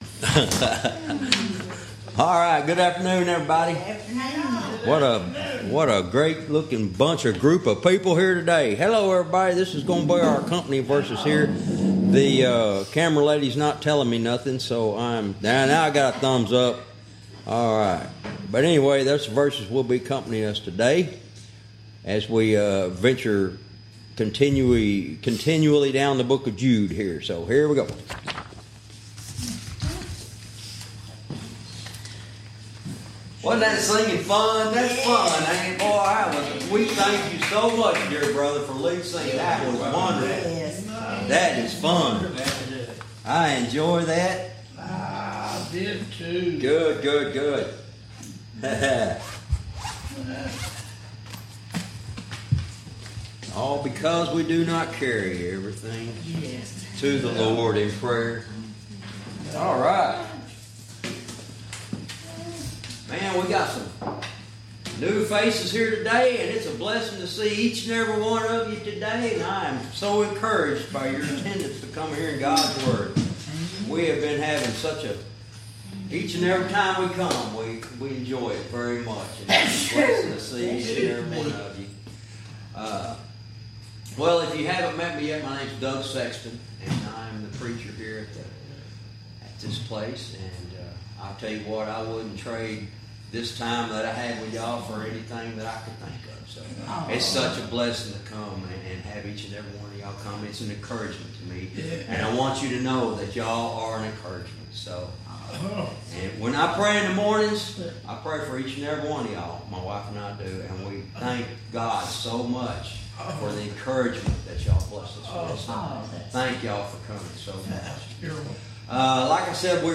Verse by verse teaching - Jude lesson 67 verse 15